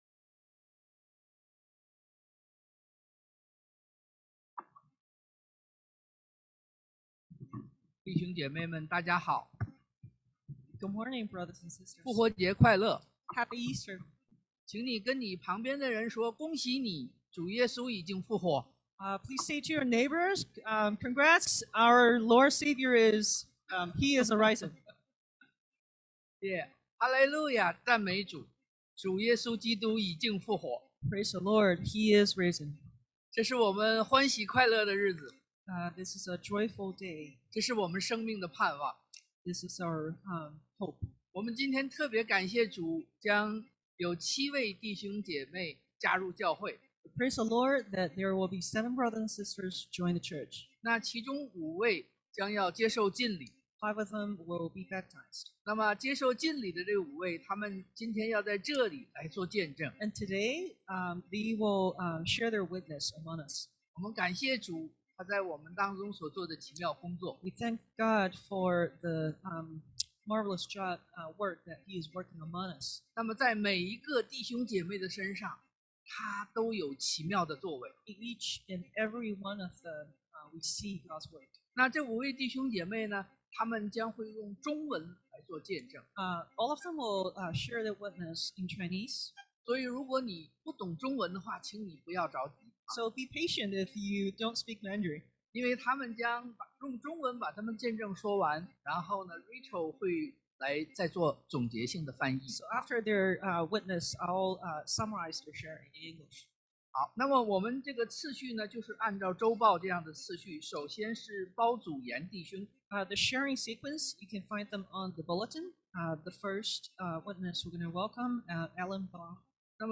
2022-04-17 Testimony 見證 (Easter Celebration 復活節)